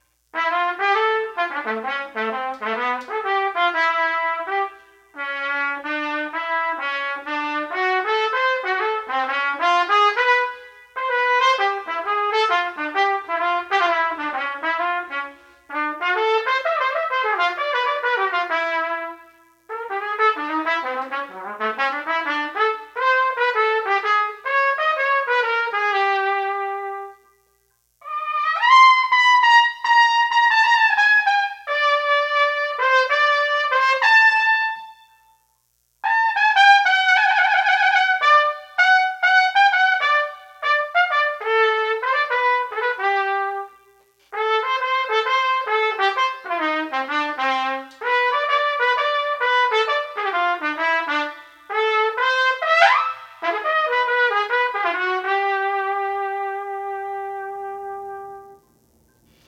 Trumpet
Swing Etude
Complete Performance
set-1-swing-etude.m4a